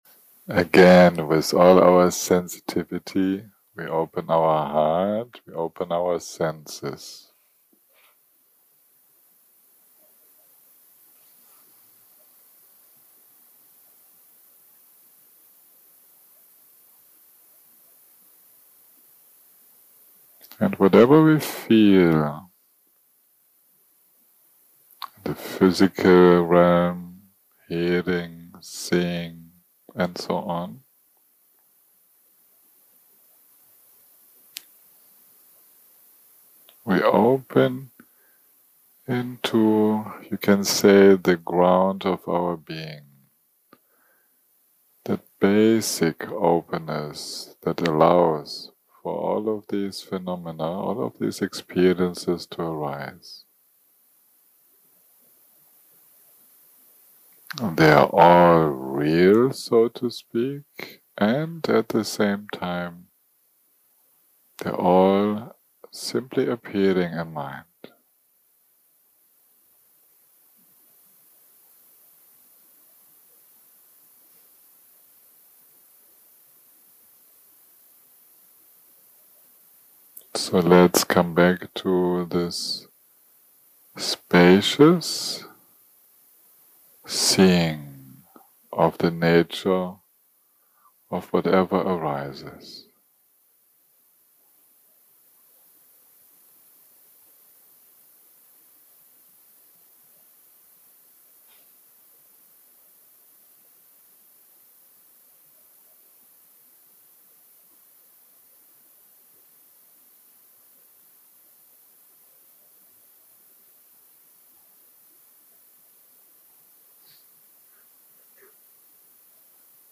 day 4 - recording 12 - Early Morning - Guided Meditation - Pranayama.
0:00 0:00 סוג ההקלטה: Dharma type: Guided meditation שפת ההקלטה: Dharma talk language: English